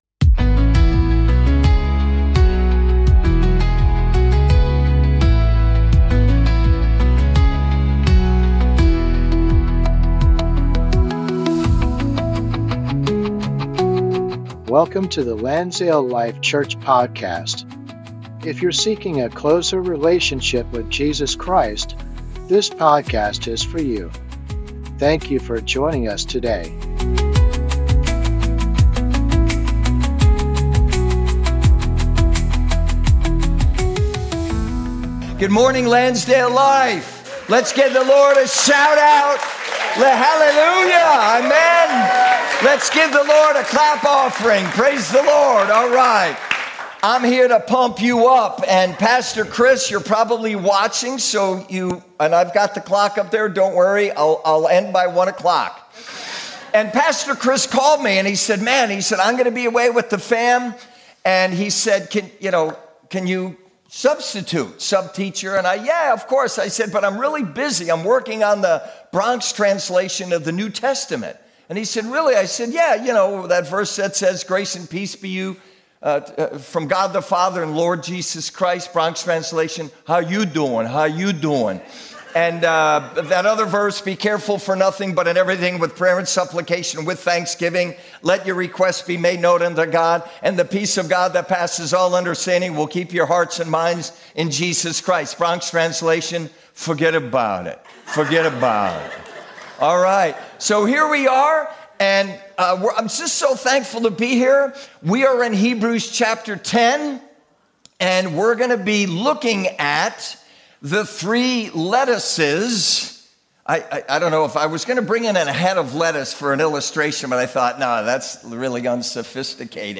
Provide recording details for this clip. Sunday Service - 2025-10-19